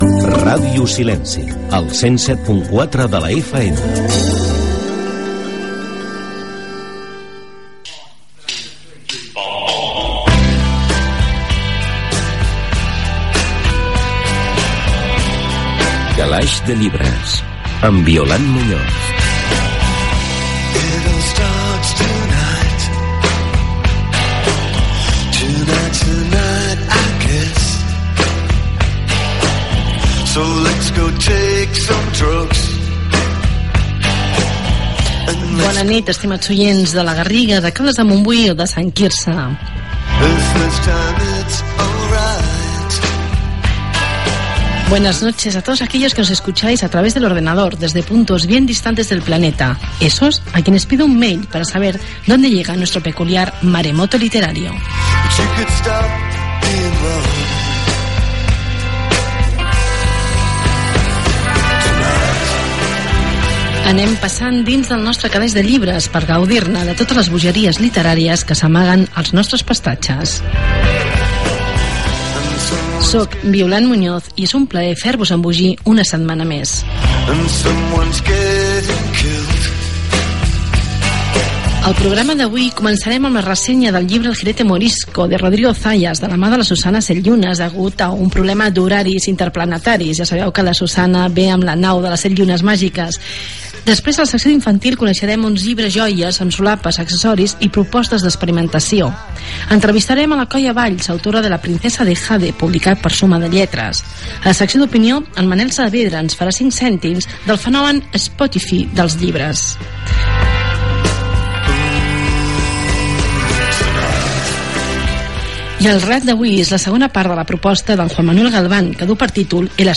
Indicatiu de la ràdio, presentació inicial, sumari
Gènere radiofònic Cultura